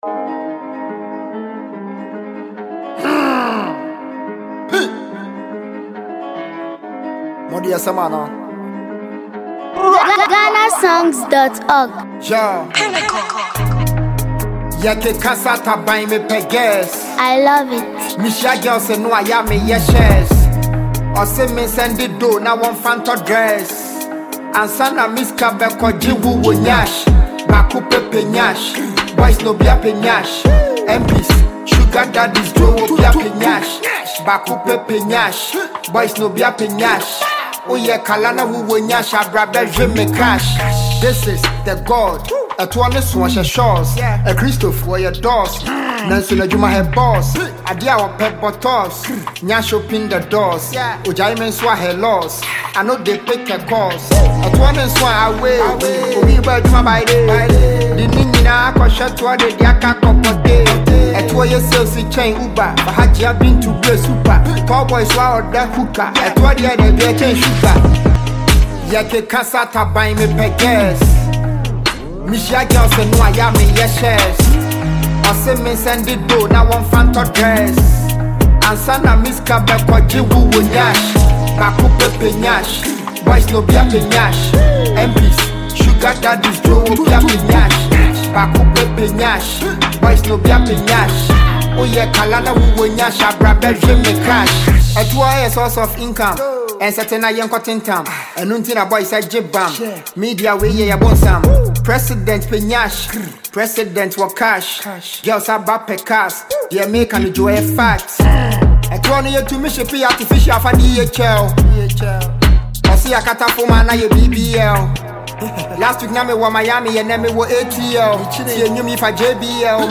rap
high heated track for the street